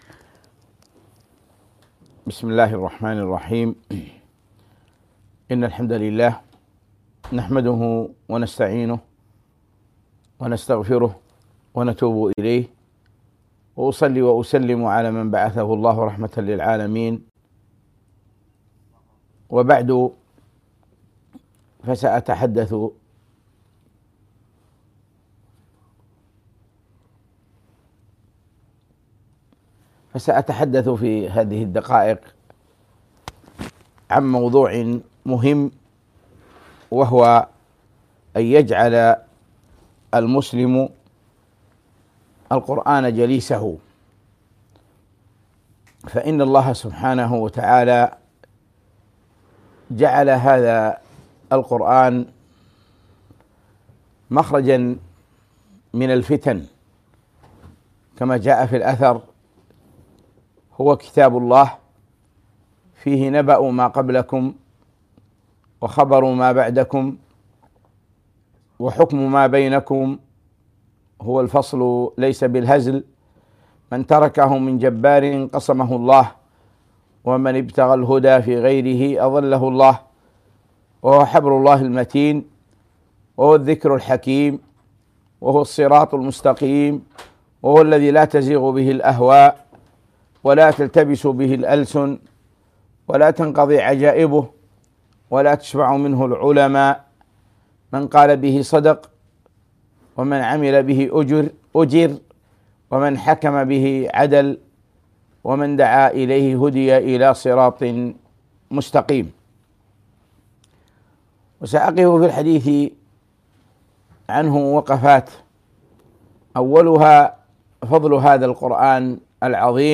محاضرة - اجعل القرآن جليسك